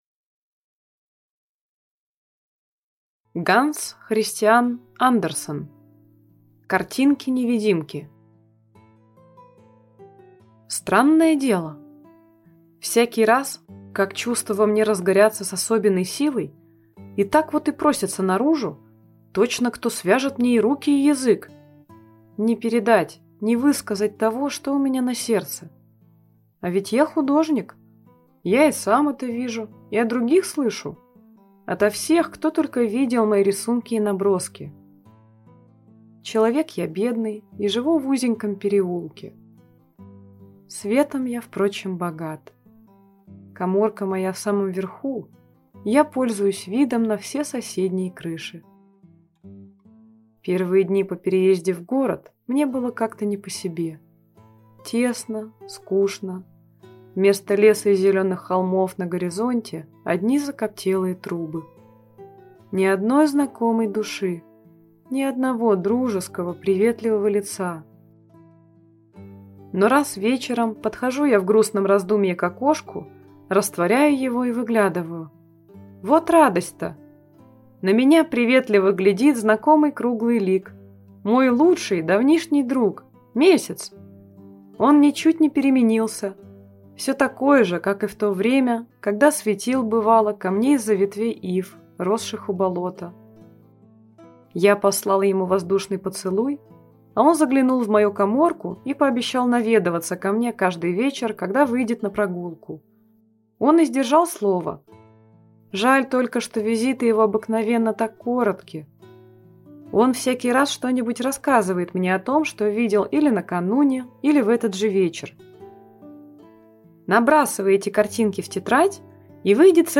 Аудиокнига Картинки-невидимки | Библиотека аудиокниг